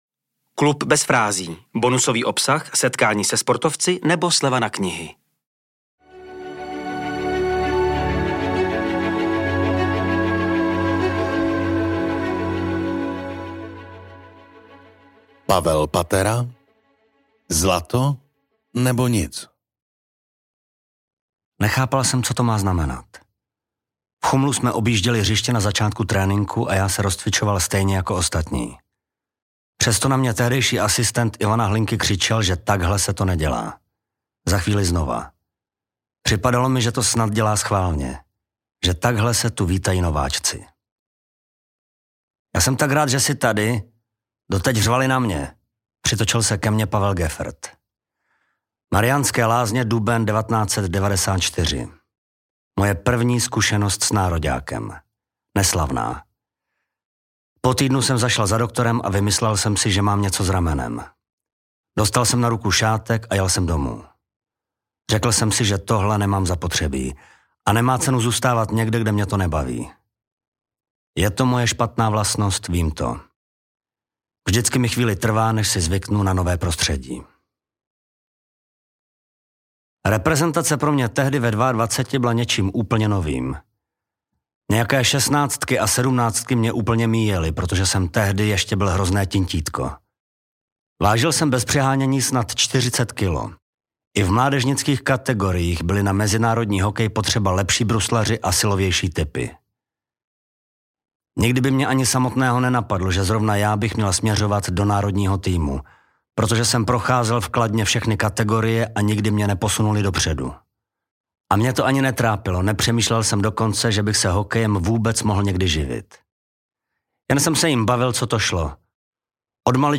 Celé vyprávění si můžete i poslechnout načtené jedinečným hlasem Martina Hofmanna .